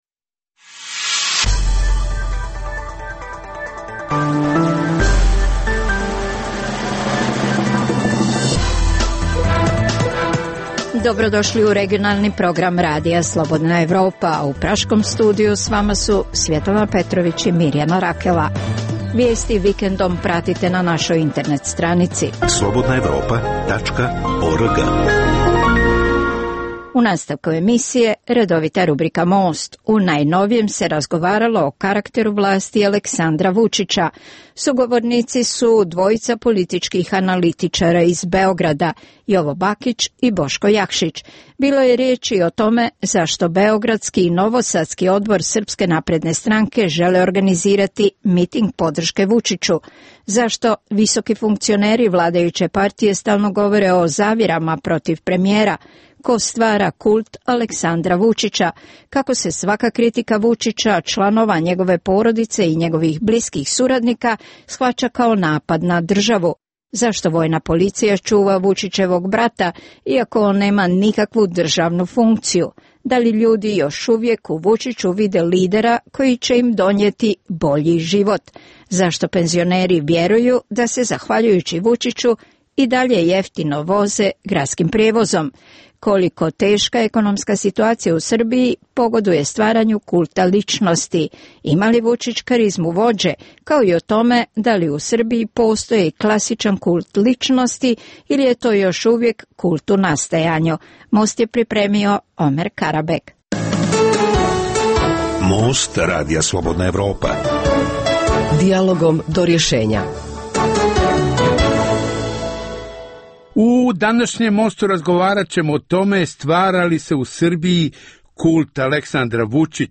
U najnovijem Mostu razgovaralo se o karakteru vlasti Aleksandra Vučića.